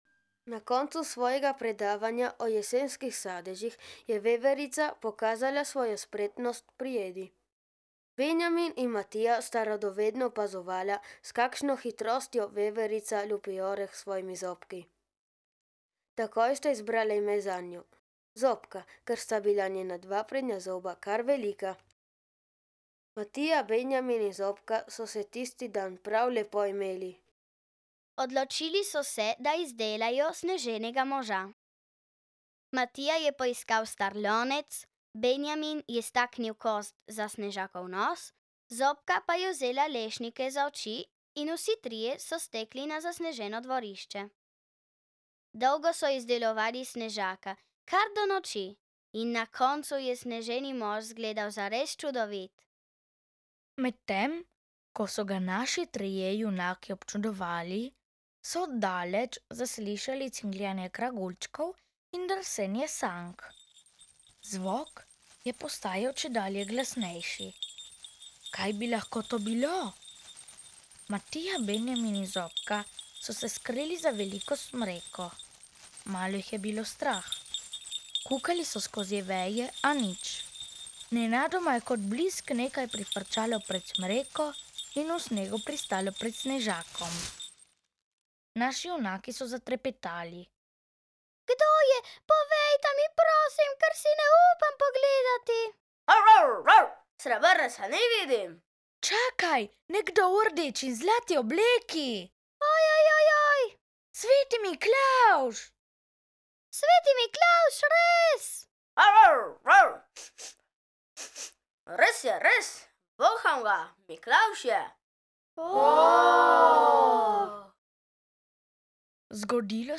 ZIMSKA GLASBENA PRAVLJICA
Besedilo in zvoki - 2385KB